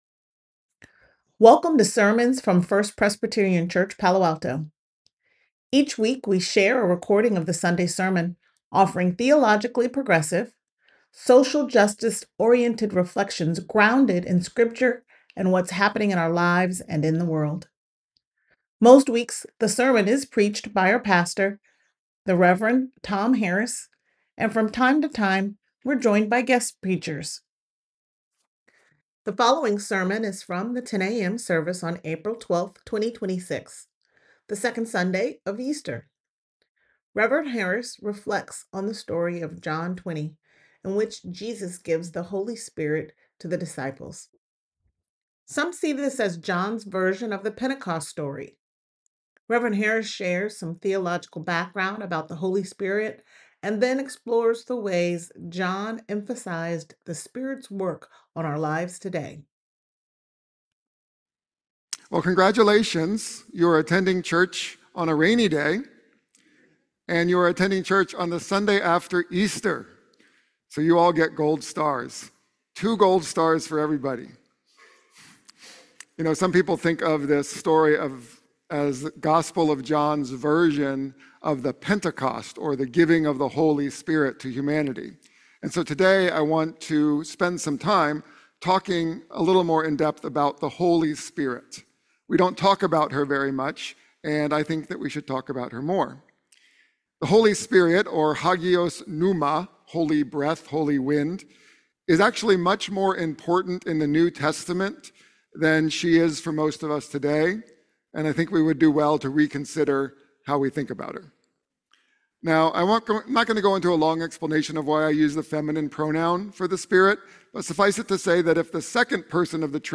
The following sermon is from the 10 a.m. service on April 12th, 2026, the second Sunday of Easter.
sermon41226a.mp3